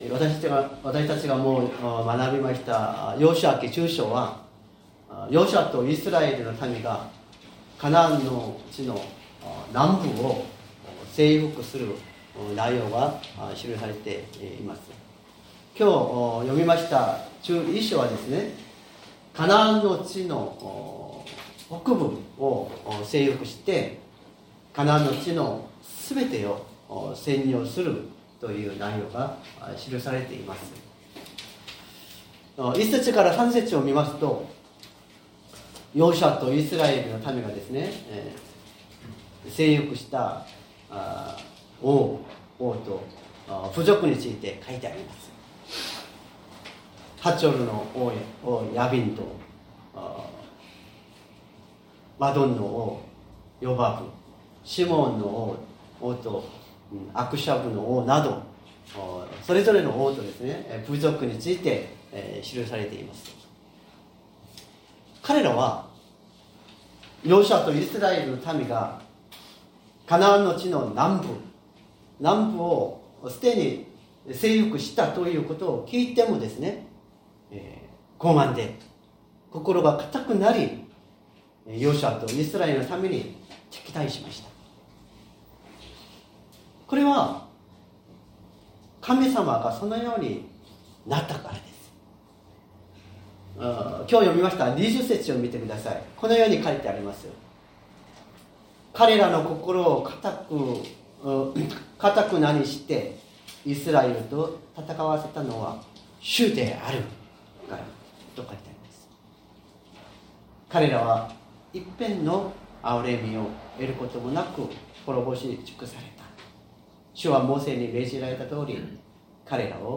善通寺教会。説教アーカイブ 2024年11月03日朝の礼拝「命じられたとおり」